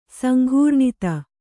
♪ sanghūrṇita